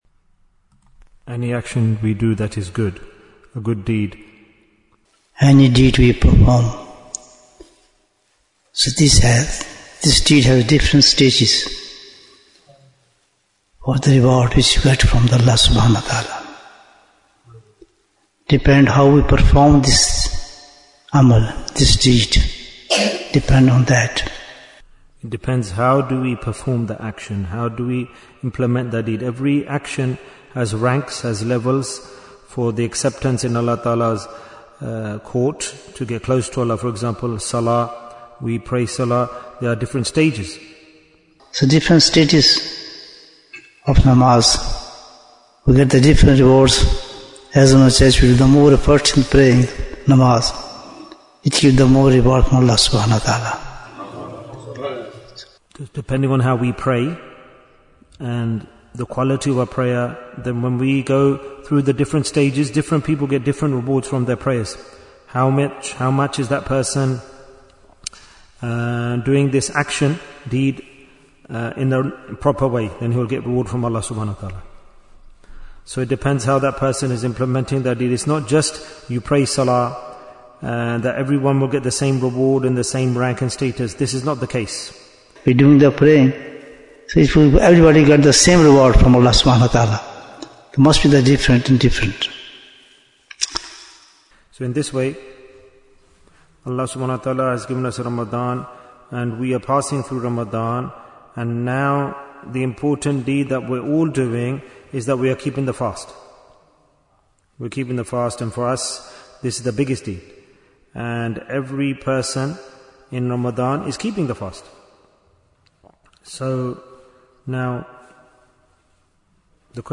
Bayan, 9 minutes